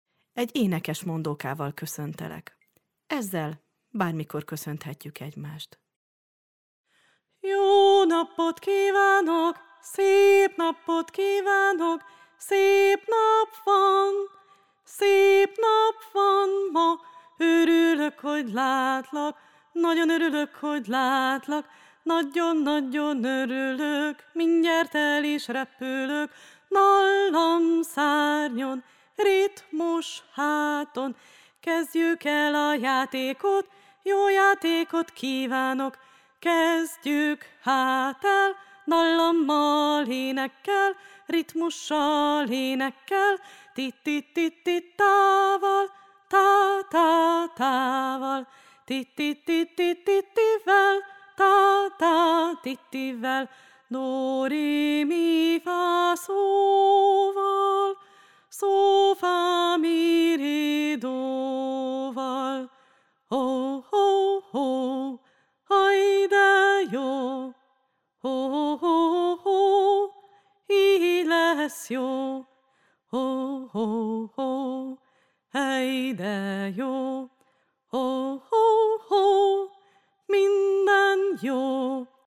Megszólalhatnak beszéd és ritmus révén, vagy épp hangmagasságokkal társulva.
JÓ NAPOT mondóka _ Köszöntő